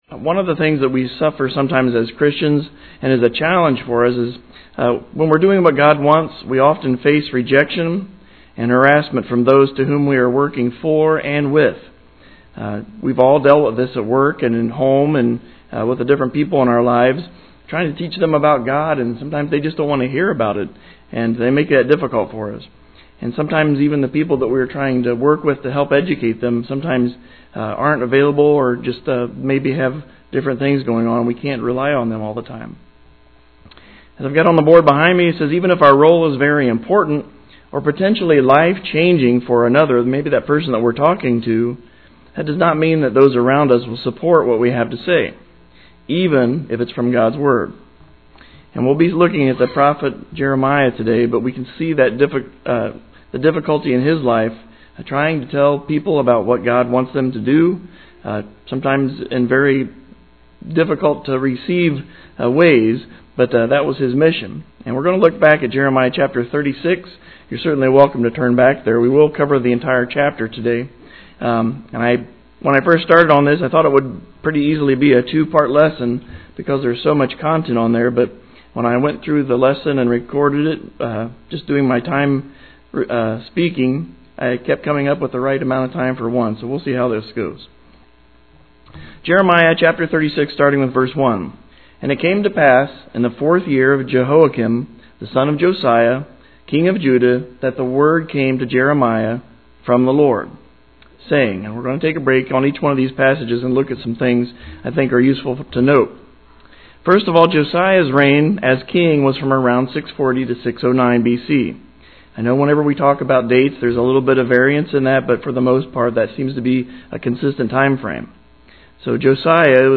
This expository lesson begins by looking at who Jeremiah was a prophet of God for, from Josiah to Jehoiakim and we see this was the time of Nebuchadnezzar.